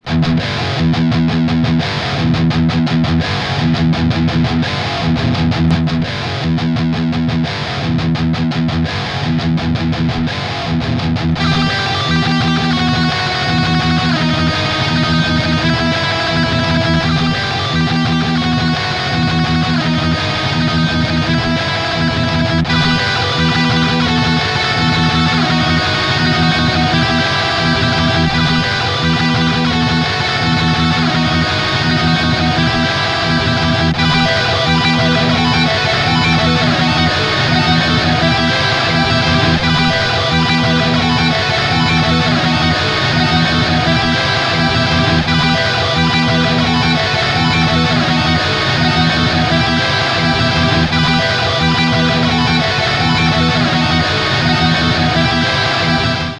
Mit ihm kannst Du deine Gitarre in Loops aufnehmen und diese Aufnahmen stapeln.
Metal Loop
tb_audio_kong_lucky_loop_metal_loop_02.mp3